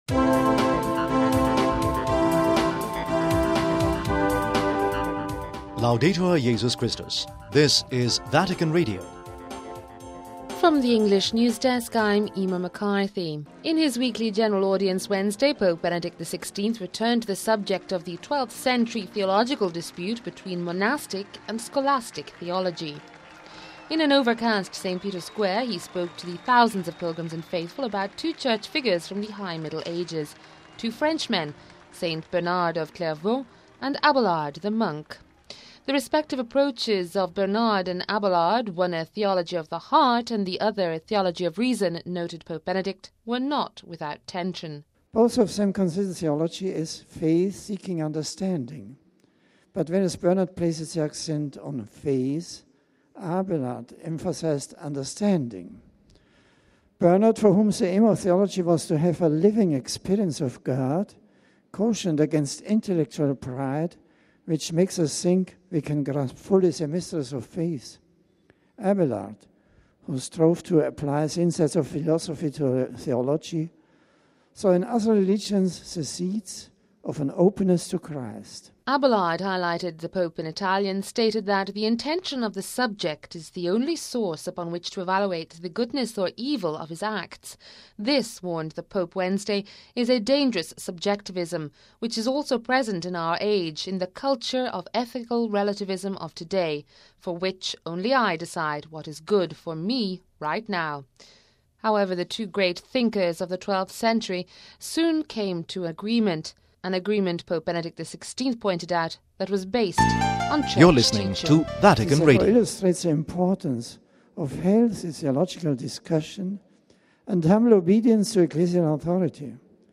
(04 Nov 09 - RV) In his weekly general audience Wednesday Pope Benedict returned to the subject of the 12th century theological dispute between monastic and scholastic theology.
In an over cast St Peter’s Square he spoke to the thousands of pilgrims and faithful about two Church figures from the High Middle Ages, two Frenchmen; Saint Bernard of Clairvaux and Abelard the monk.